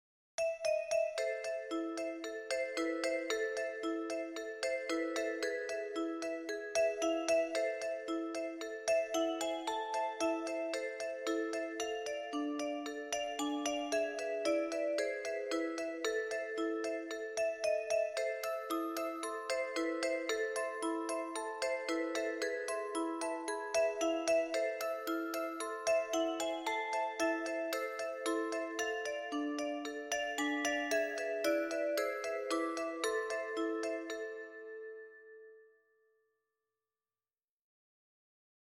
folk song America